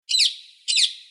دانلود آهنگ پرنده 7 از افکت صوتی انسان و موجودات زنده
جلوه های صوتی
دانلود صدای پرنده 7 از ساعد نیوز با لینک مستقیم و کیفیت بالا